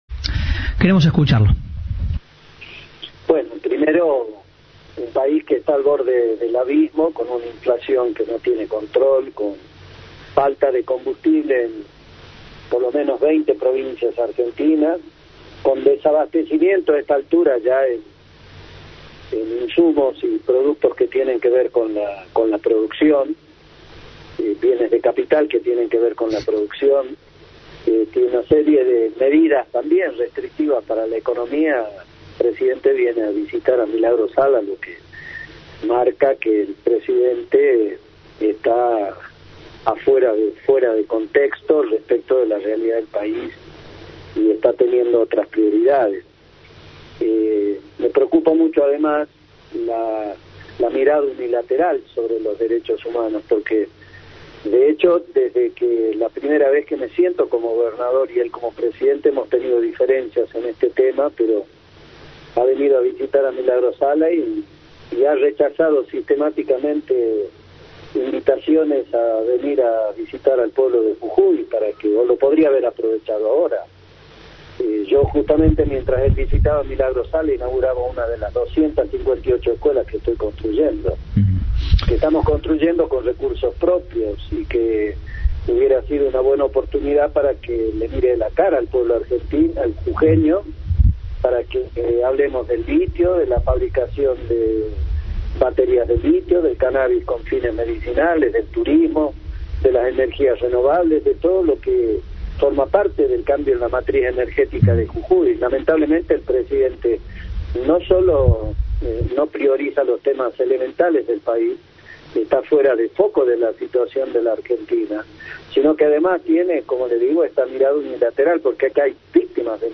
El gobernador jujeño dijo a Cadena 3 que Alberto Fernández no está en sintonía con la realidad de la país. Habló de las condenas de la dirigente social.